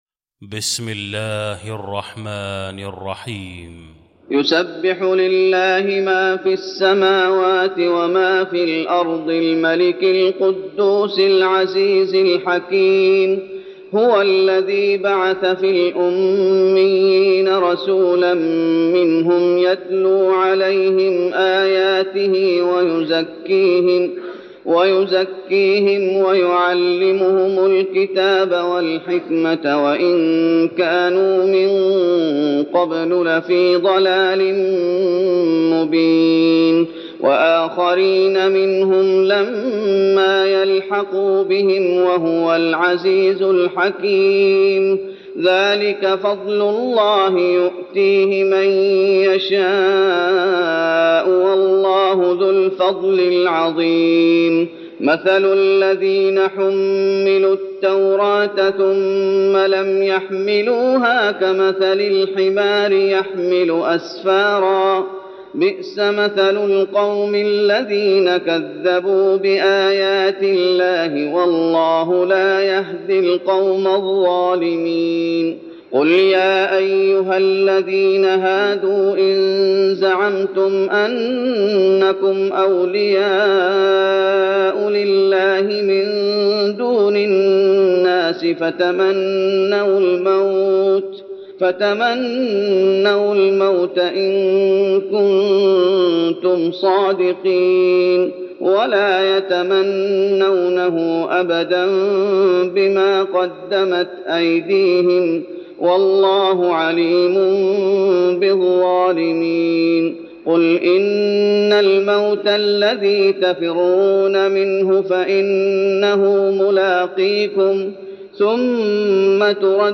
المكان: المسجد النبوي الجمعة